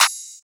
CLAP (WAKE UP).wav